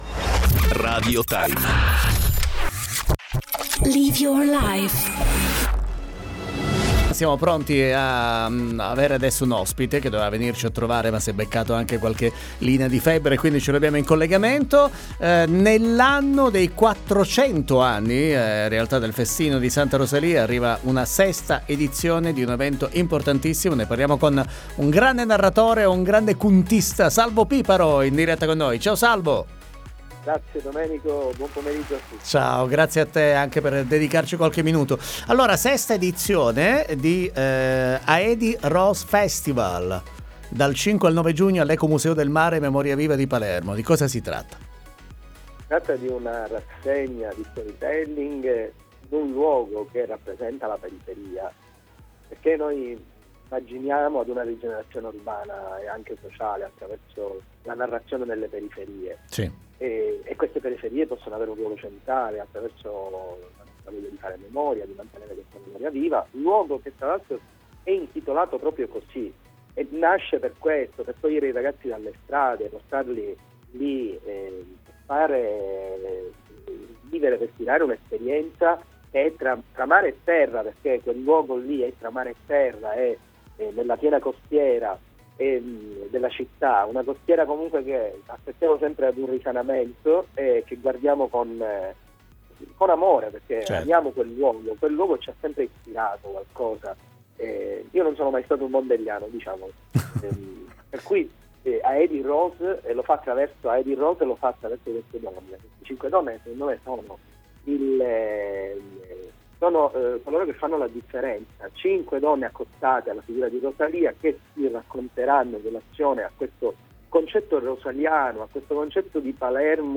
PRESENTA “AEDI ROSE FESTIVAL” ALL’ECOMUSEO DEL MARE DI PALERMO fast n curios Interviste 03/06/2024 12:00:00 AM